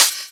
VEC3 Cymbals HH Open 074.wav